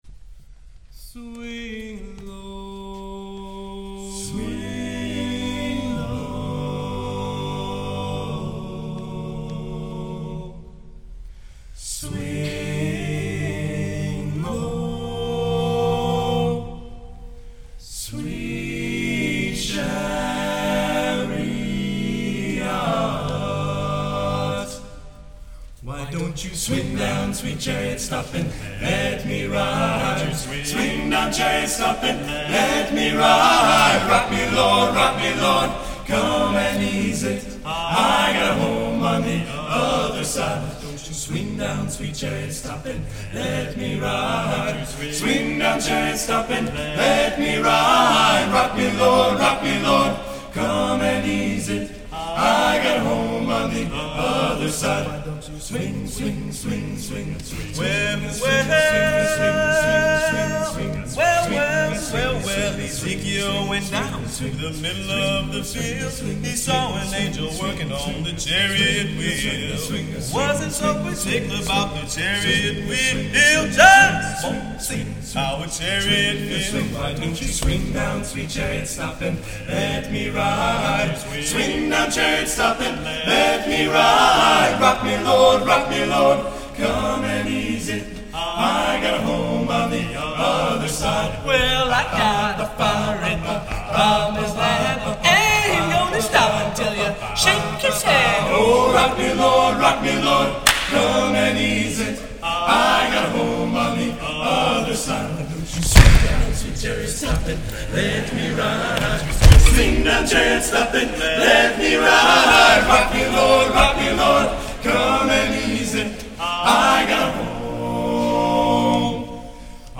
CD, 1998